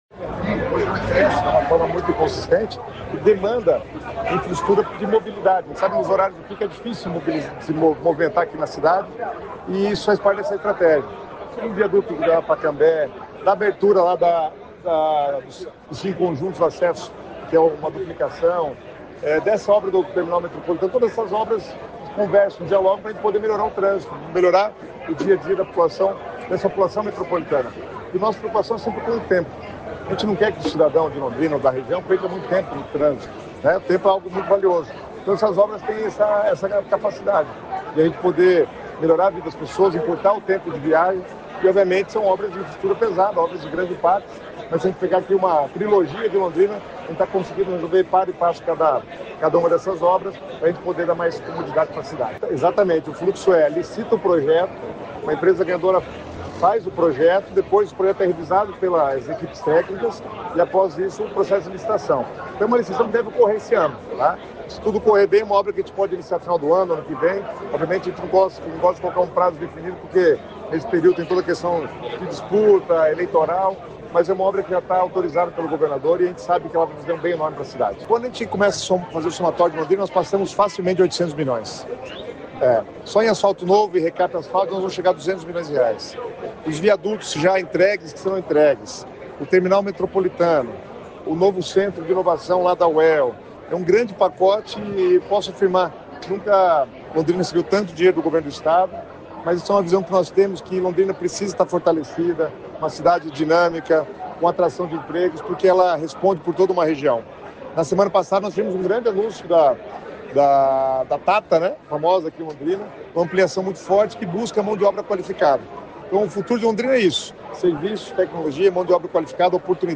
Sonora do secretário Estadual das Cidades, Guto Silva, sobre o novo Terminal Metropolitano de Londrina